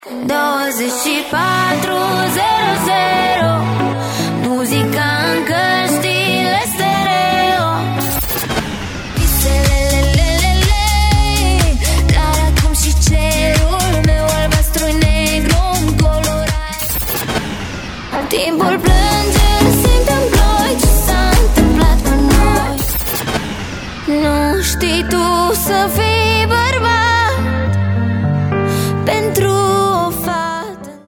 Cu acestă ocazie, artista a acordat un interviu în exclusivitate pentru Radio Romania Brașov, în care a povestit despre cum a primit invitația de a participa la Cerbul de Aur și ce amintiri are despre celebrul festival.